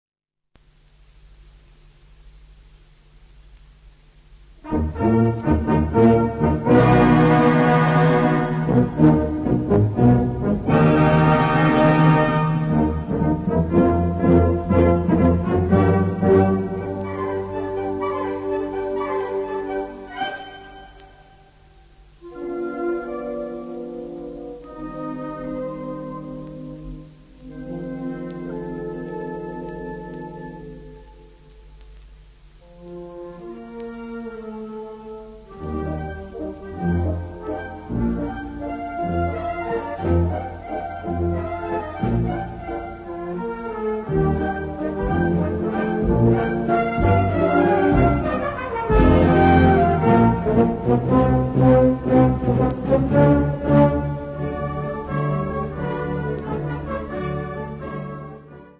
Gattung: Potpourri
Besetzung: Blasorchester